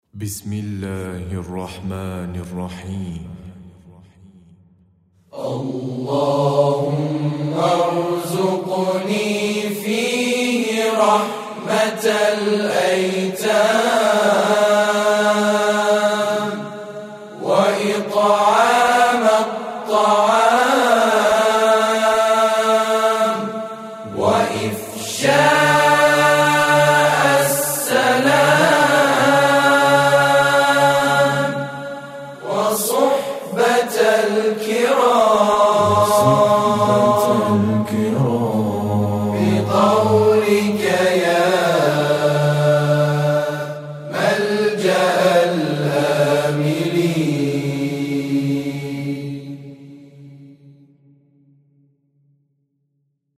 نماهنگ و سرود رسمی و معنوی